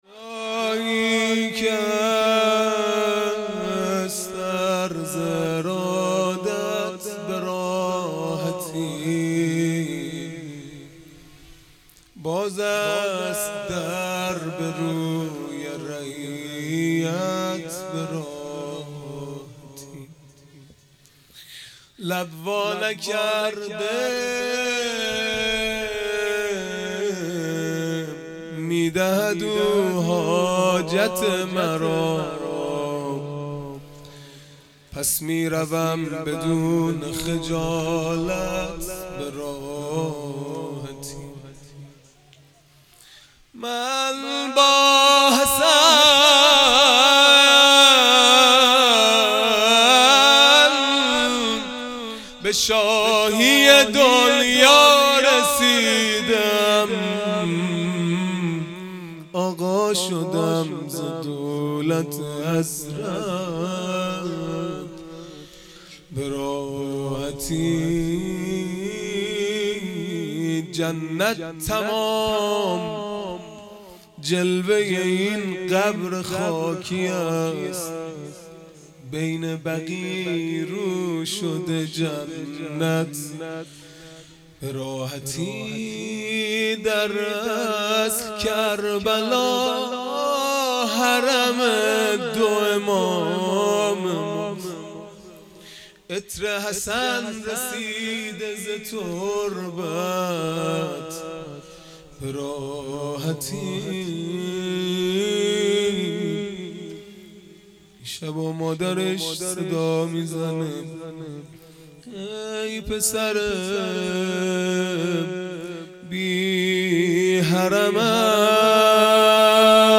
مناجات پایانی | جایی که هست عرض ارادت به راحتی | شنبه ۲۳ مرداد ۱۴۰۰
دهه اول محرم الحرام ۱۴۴۳ | شب ششم | شنبه ۲۳ مرداد ۱۴۰۰